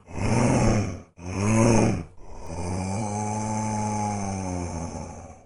zombie_moan_3p2.mp3